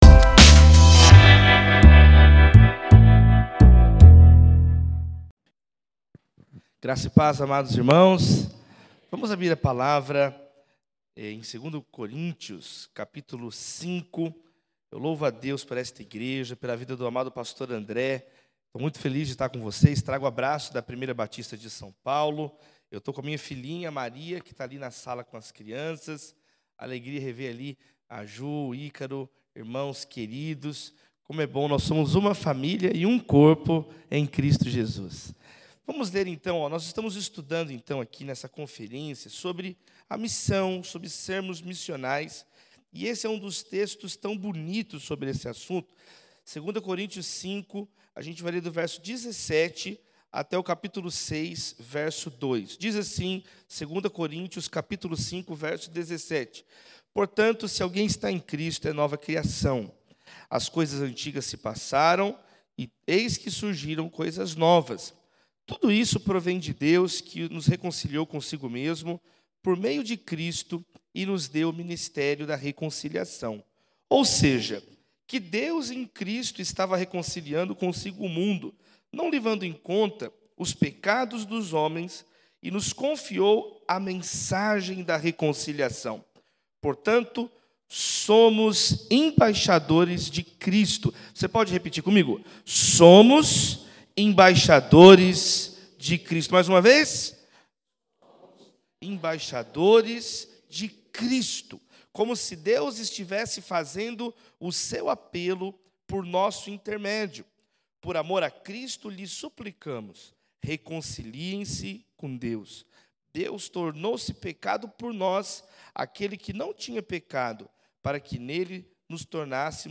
Conferência Missionária de 2022.